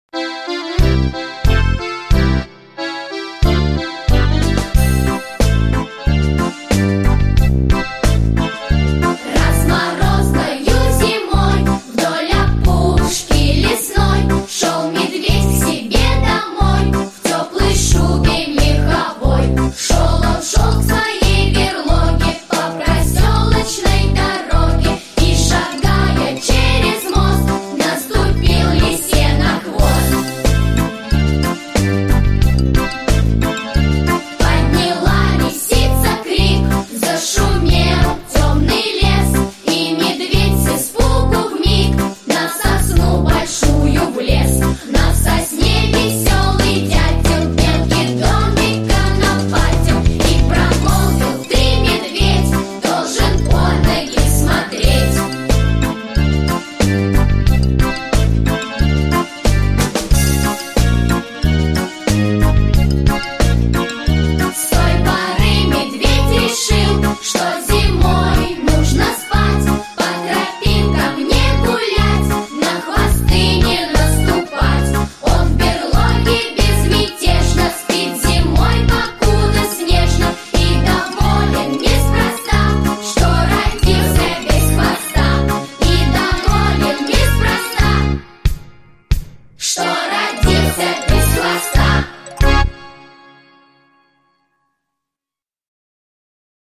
Песенки про зиму